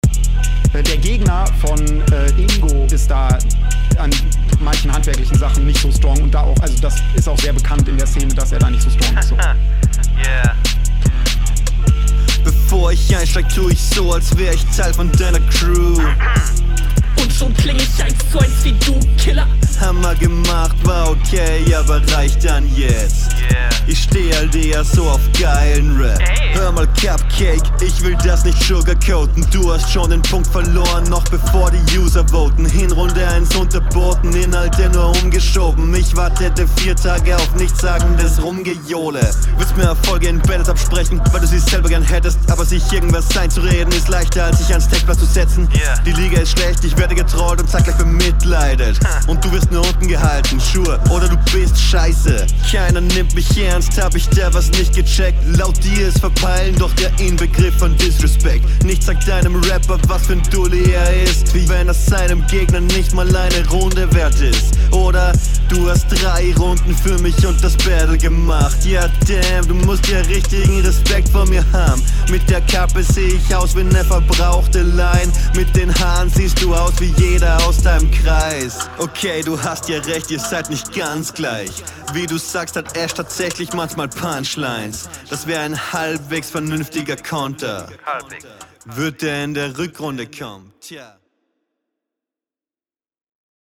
Intro gut gekontert, Flow bisschen druckloser aber Mix cleanerer, gut gekontert, Reime viel besser